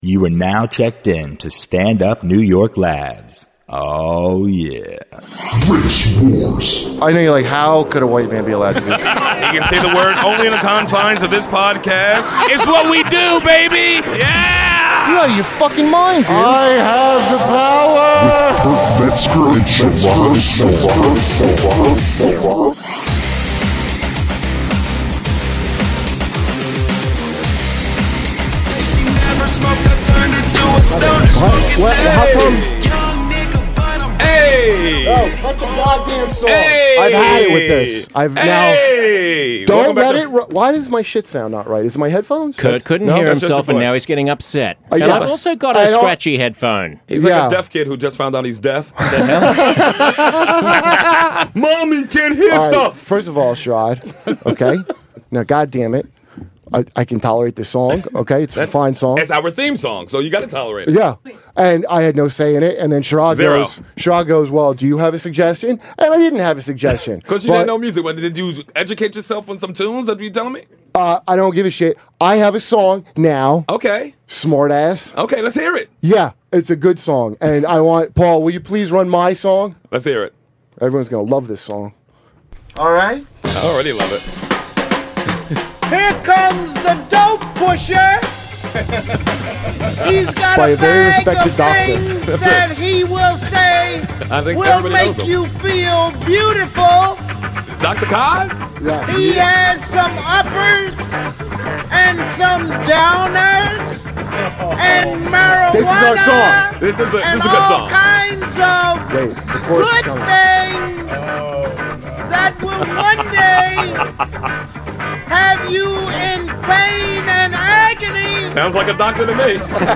Comedian Michael Che and host of Huff Post Live Josh Zepps are in the studio this week.